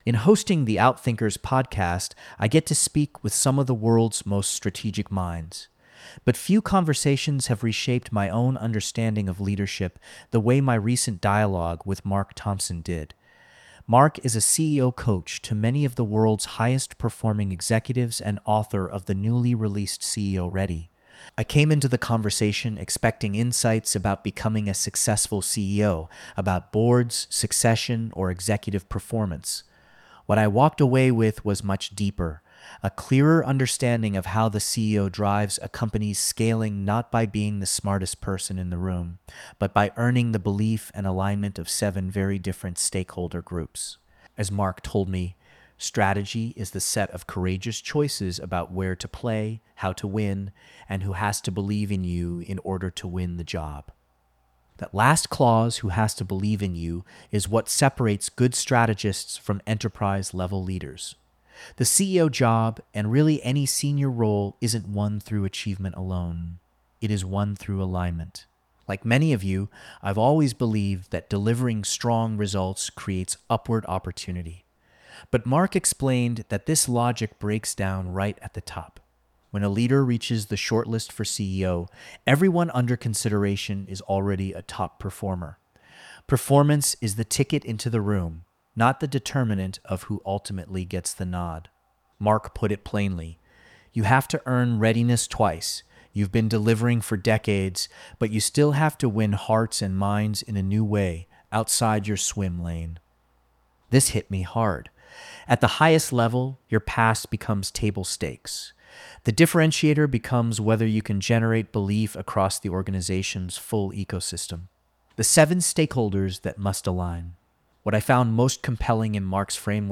This audio was recorded by AI.